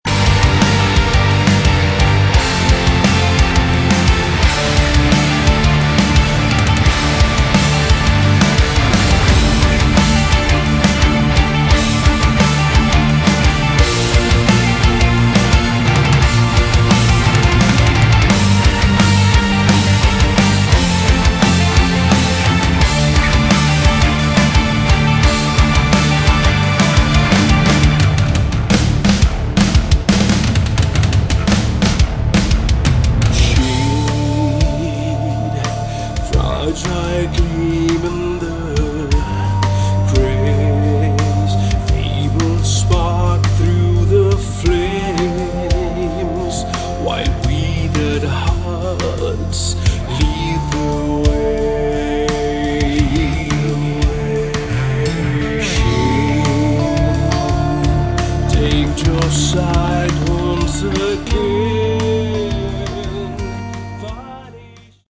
Category: Rock
keyboards, backing vocals